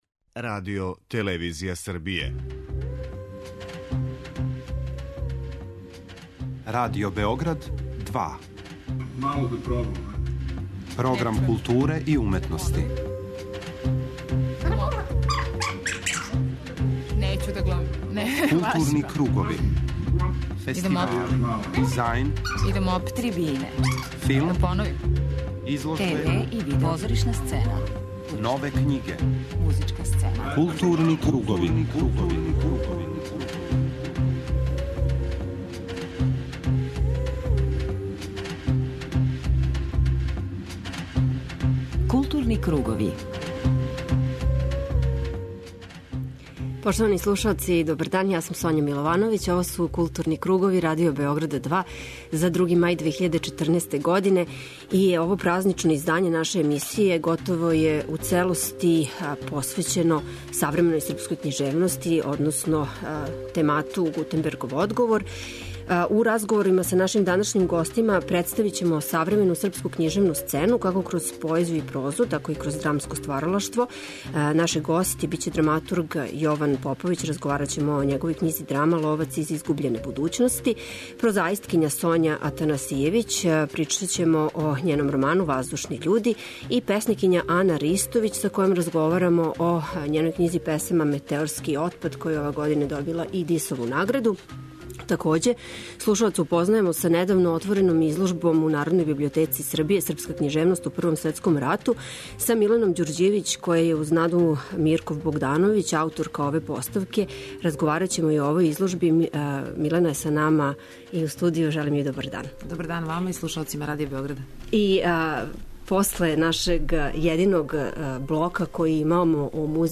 У разговорима са нашим данашњим гостима представљамо савремену српску књижевну сцену, како кроз поезију и прозу, тако и кроз драмско стваралаштво.